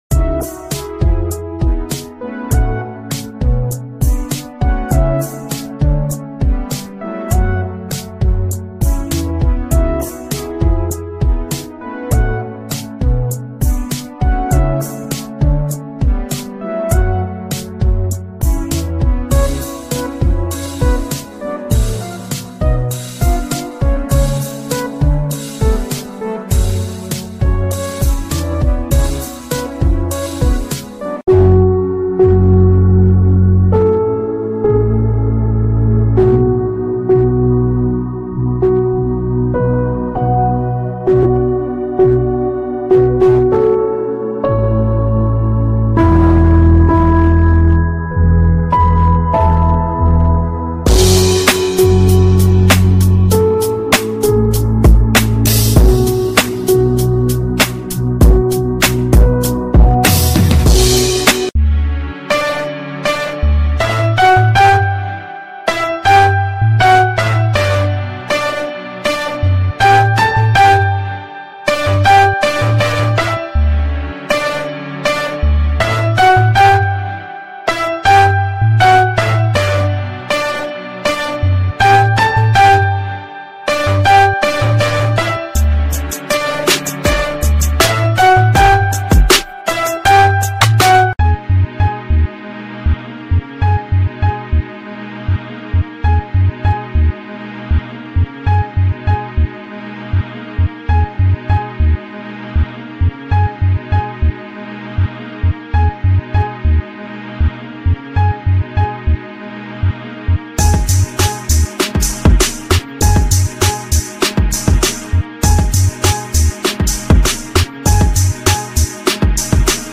🇲🇽 Playa del Carmen ｜ 5th Avenue Ooaxaca ｜ Mexico Walking Tour-001